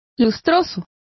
Complete with pronunciation of the translation of glossier.